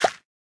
Minecraft Version Minecraft Version 25w18a Latest Release | Latest Snapshot 25w18a / assets / minecraft / sounds / block / composter / empty2.ogg Compare With Compare With Latest Release | Latest Snapshot